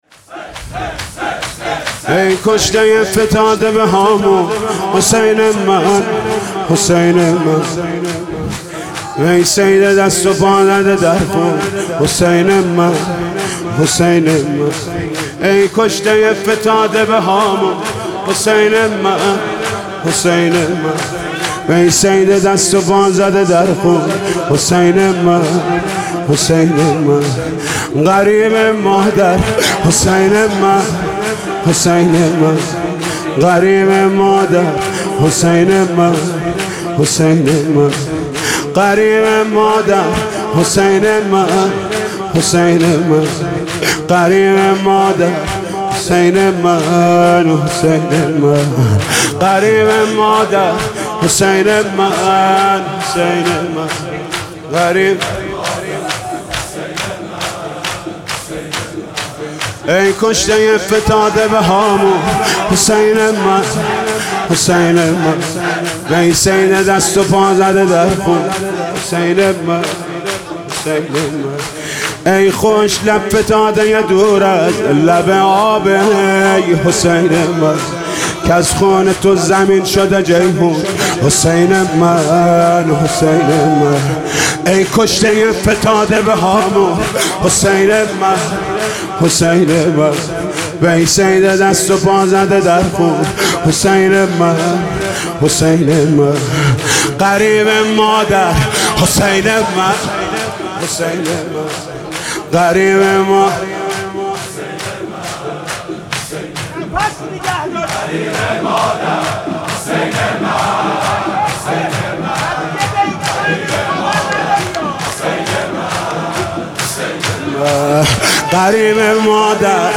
/ فیلم برچسب‌ها: مرثیه مداحی مذهبی حاج محمود کریمی دیدگاه‌ها (3 دیدگاه) برای ارسال دیدگاه وارد شوید.